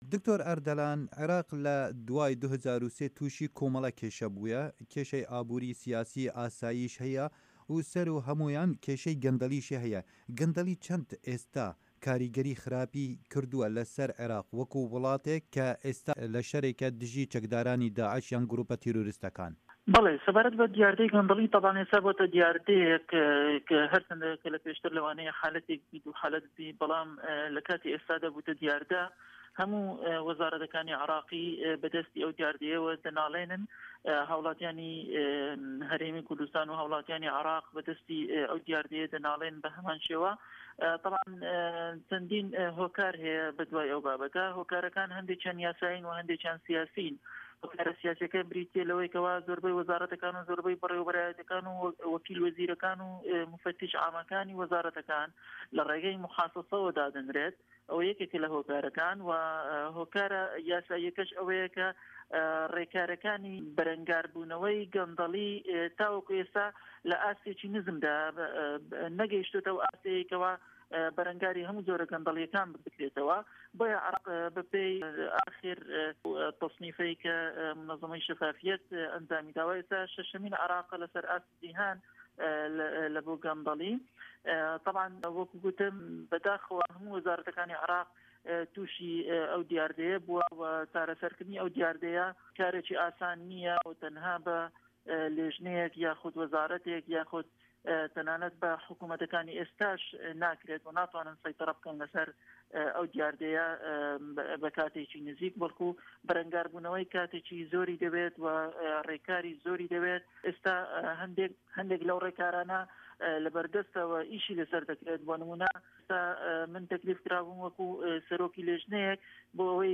Di hevpeyvînekê de ligel Dengê Amerîka, endamê parlamena Îraqê Dr. Erdelan Nûredîn got, şêwaza birêvebirina Îraqê û dabeşkirina desthilatê ya xirab, ev welat ketiye ber gendeliyeke mezin.
Hevpeyvin digel Dr.Erdelan Noredin